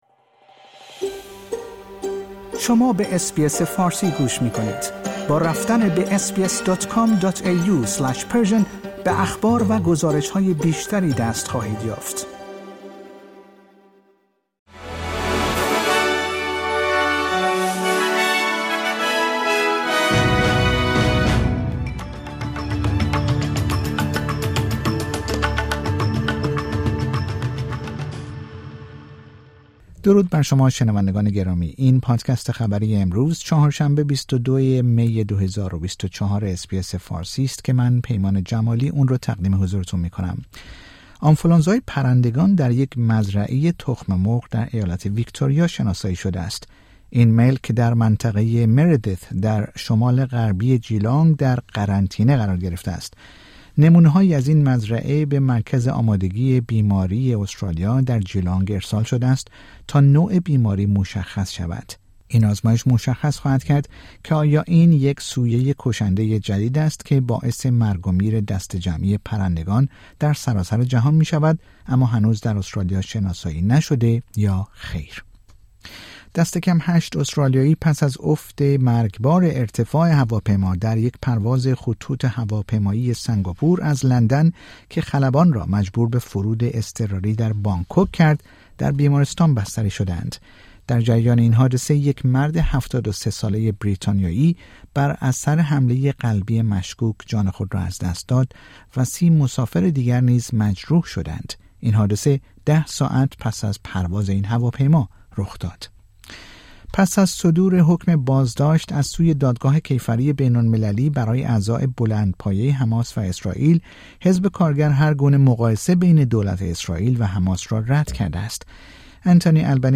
در این پادکست خبری مهمترین اخبار استرالیا، در روز پنجشنبه ۲۳ مه ۲۰۲۴ ارائه شده است.